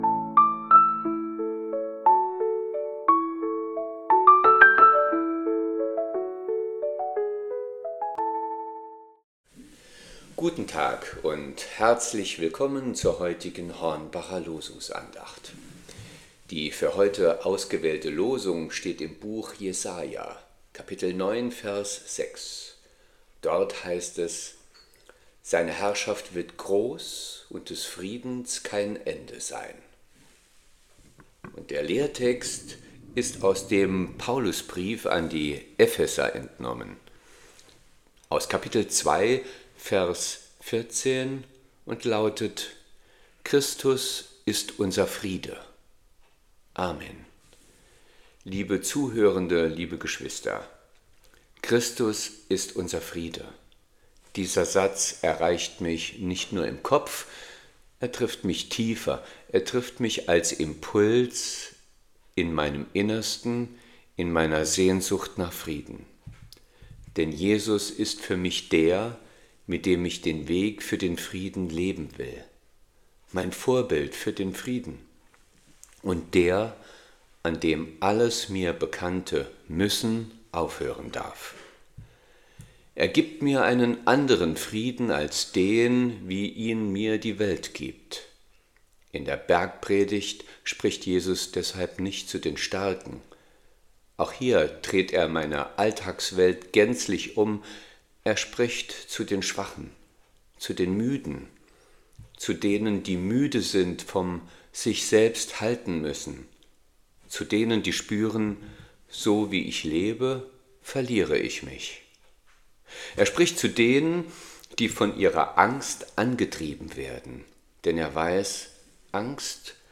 Losungsandacht für Samstag, 07.02.2026